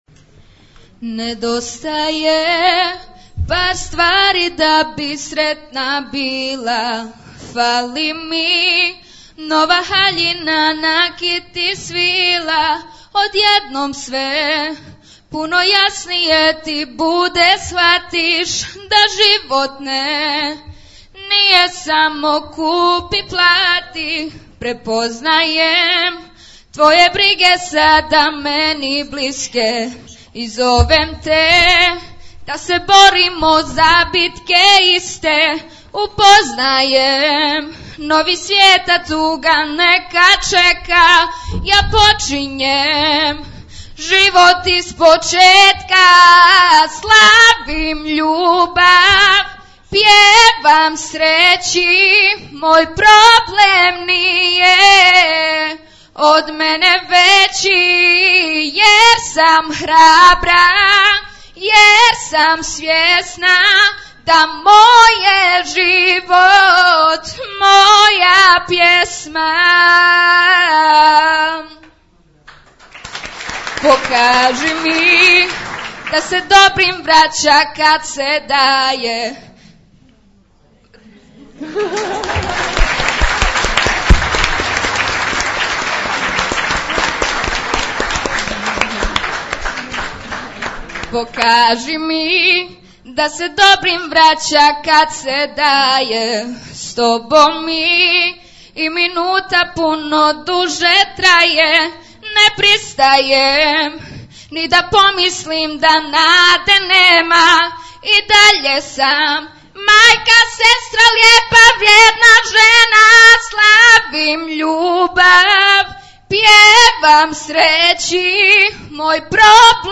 Za sve vas koji niste uspjeli doći na književnu večer